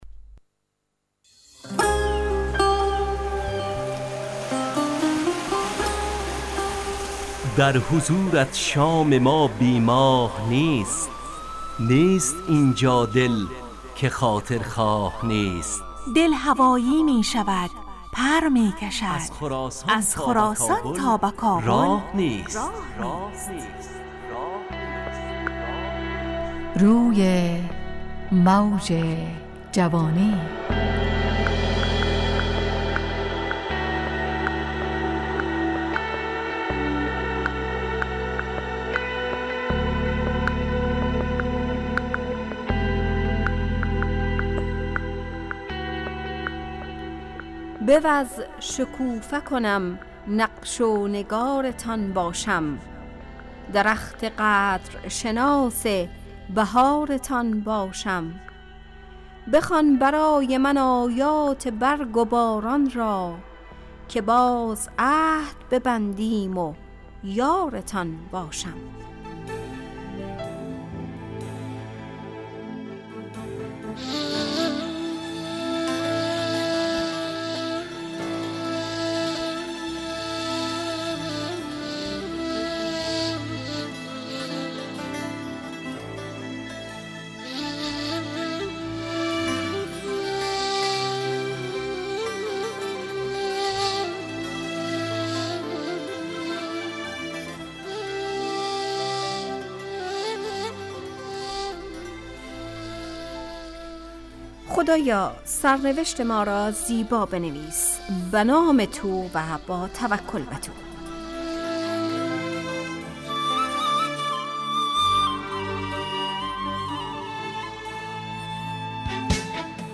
همراه با ترانه و موسیقی مدت برنامه 70 دقیقه . بحث محوری این هفته (قدرشناسی) تهیه کننده